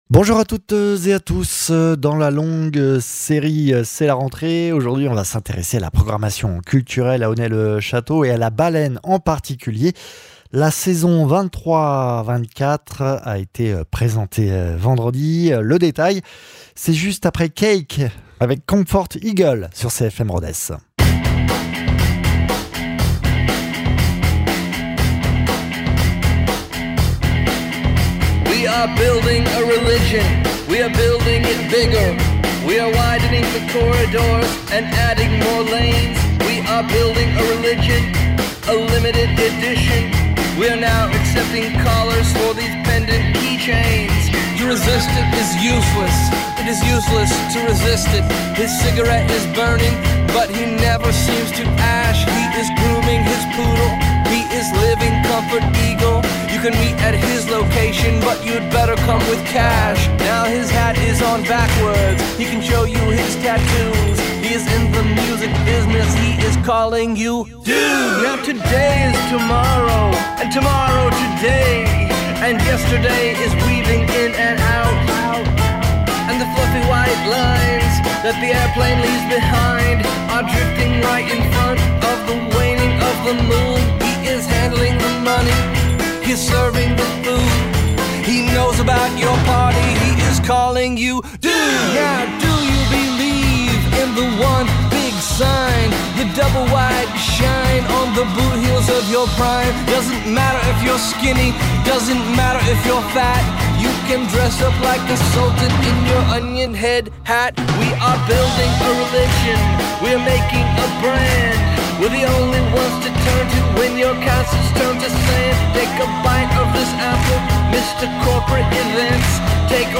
Jean Philippe Kéroslian, Maire d’Onet le Château